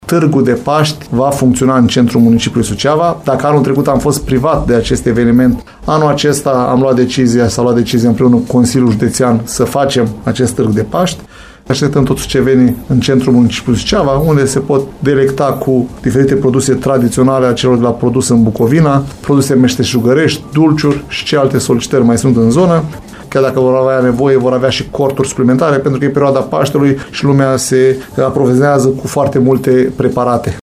Comercianții și meșterii populari locali își pot vinde produsele în căsuțele de lemn ale Asociației Produs în Bucovina până pe 9 mai, după cum a declarat la IMPACT FM viceprimarul LUCIAN HARȘOVSCHI.